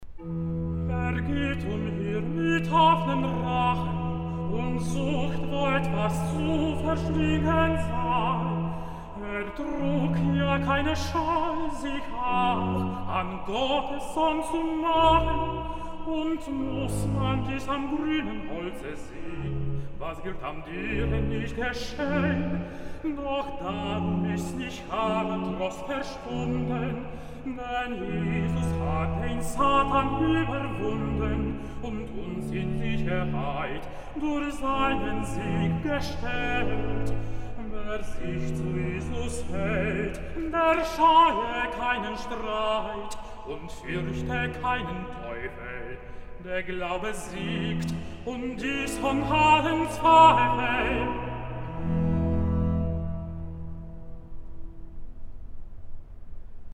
Kantate